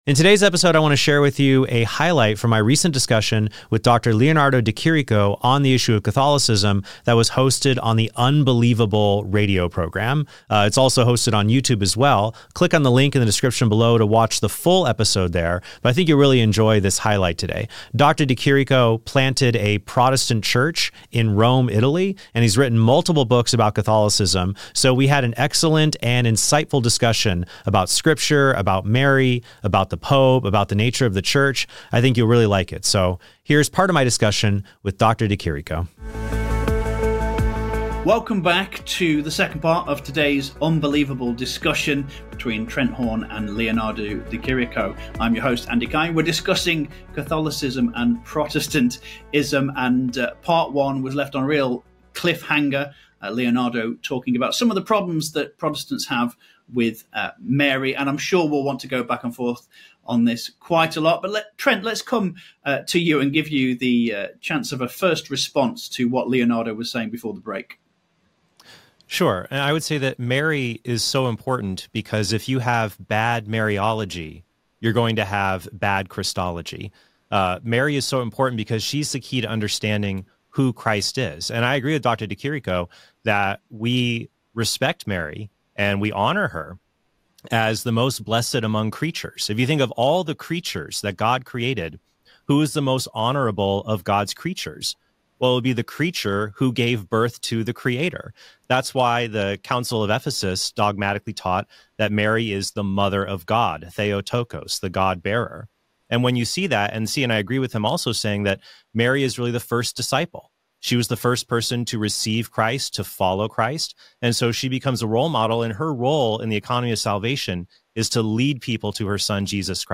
969-Debating-Catholicism-with-a-Roman-Protestant-.mp3